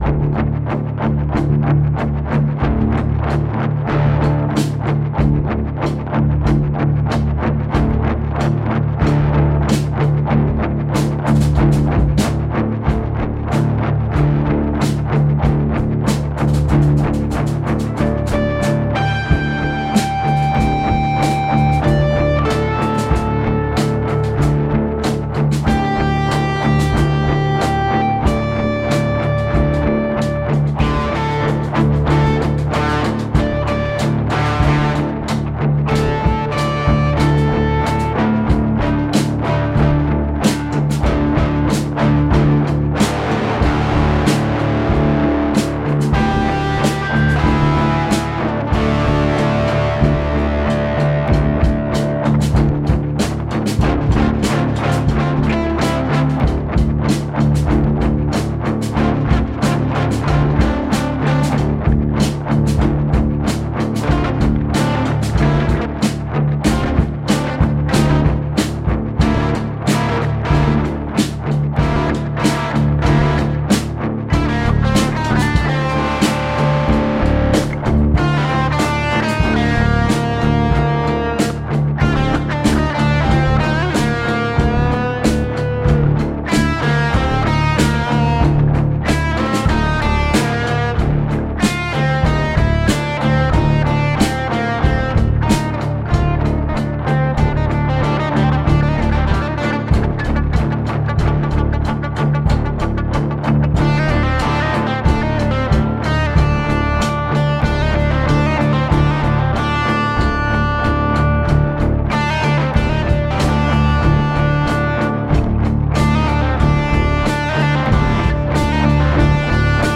guitar, drums, bass. 3 bests
Started with the guitar, and the 3rd is distorted.
guitarcore_3_of_3_mix.mp3